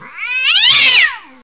Angry Cat Meow